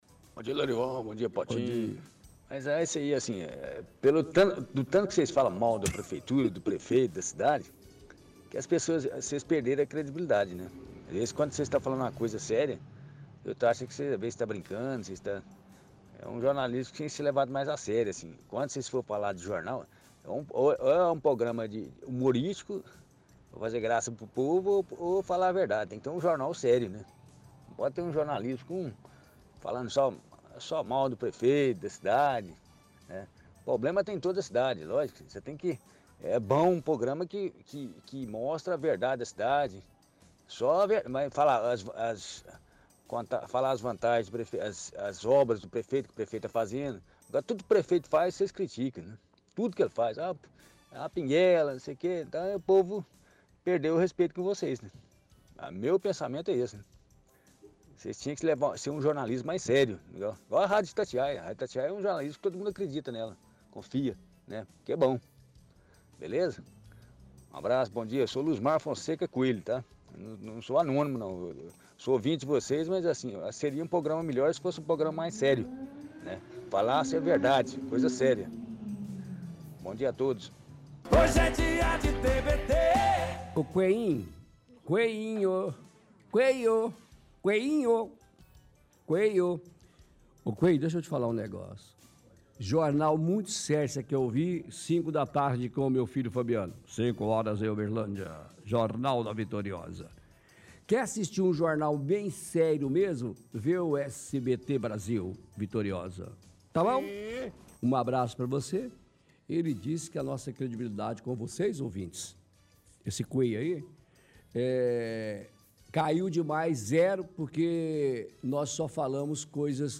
Áudio antigo de ouvinte